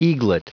Prononciation du mot eaglet en anglais (fichier audio)
Prononciation du mot : eaglet